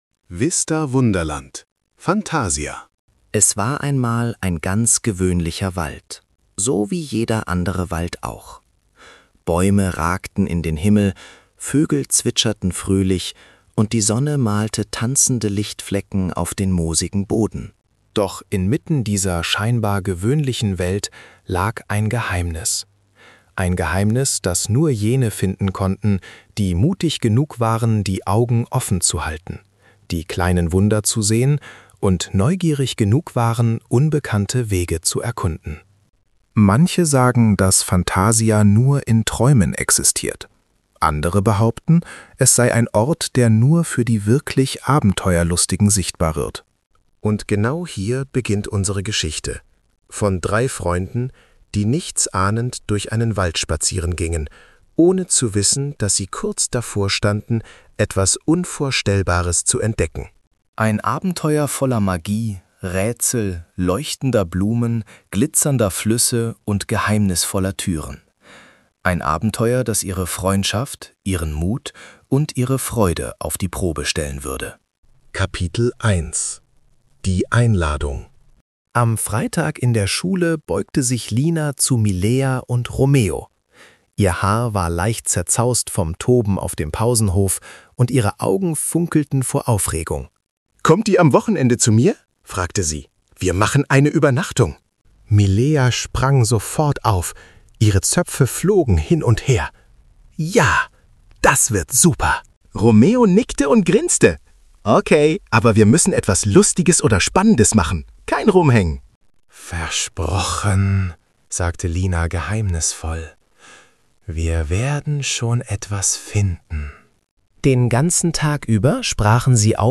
VISTAWonderland Hörbuch
MP3-Hörbuch